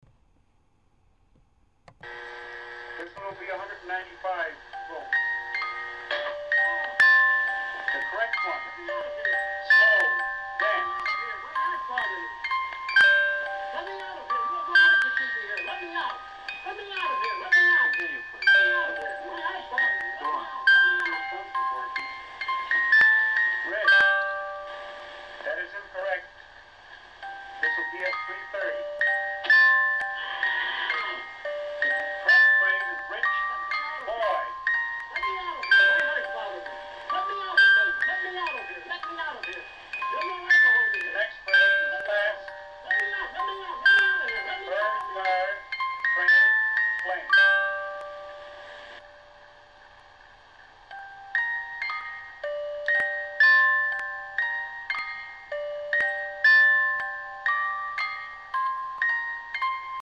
Triplets have never sounded so unsettling. This is the main title music for the horror movie i may or may not ever film.